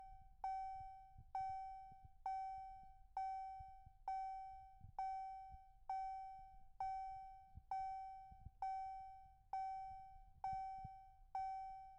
car lights ding
ambience auto car cars city ding field-recording headlights sound effect free sound royalty free Sound Effects